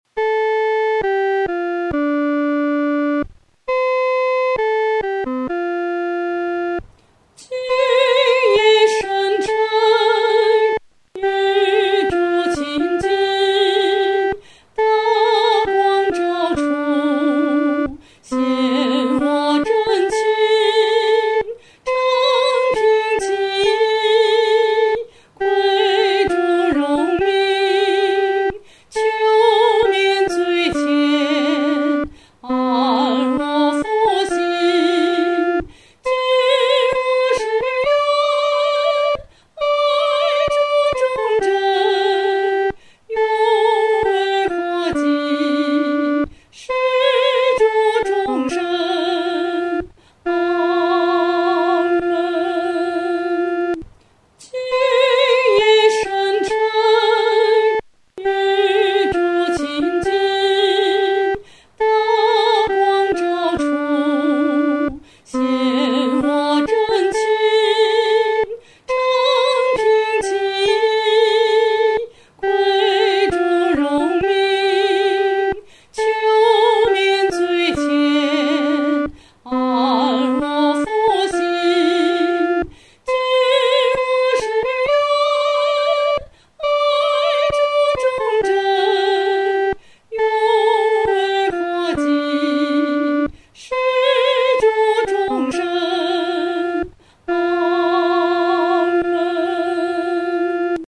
合唱
女高
曲调中每一句都有一个四分休止符，不是为了让歌者换气用的，而是为了留些时间，让自己多思想当时是如何响应主的呼召。
这首献心志的诗歌，我们当带着恳切、敬虔的心，用不快的中速弹唱。